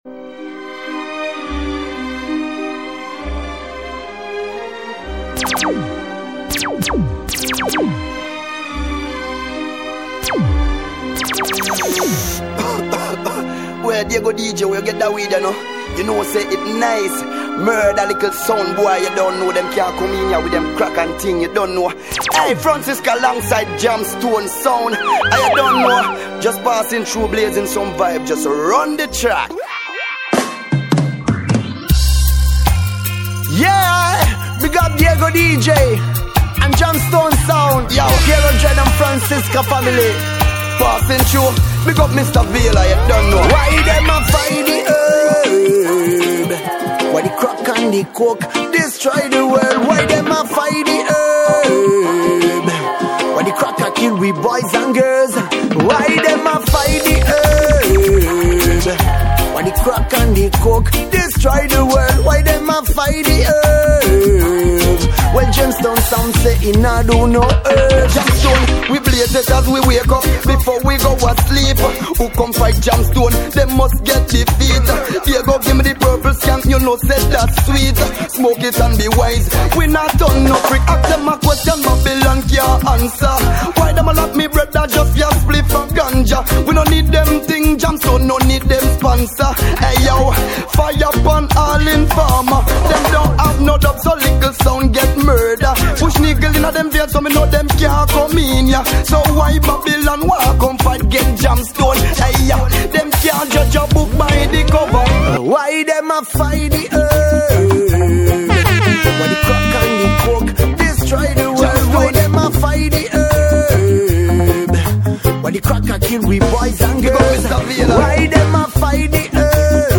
BEST DJ MIX